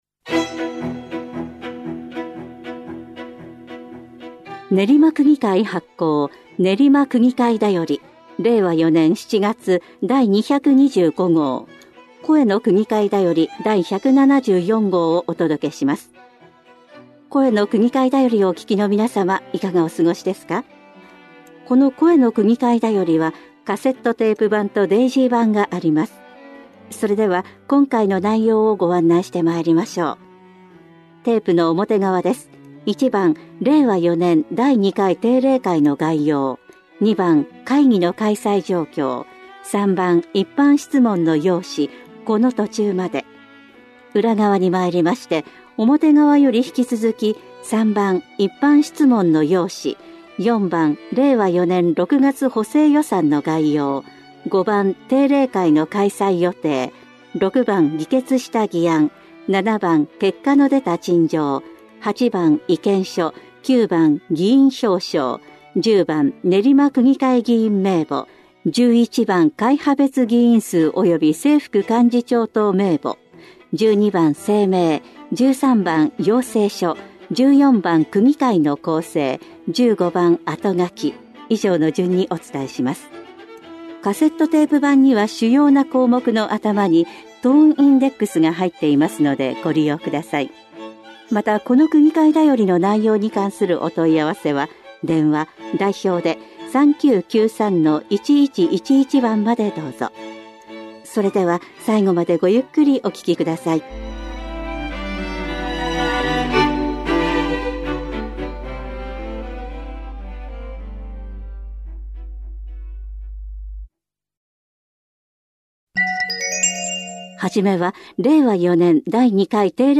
練馬区議会では、目の不自由な方のために、カセットテープとデイジーによる「声の区議会だより」を発行しています。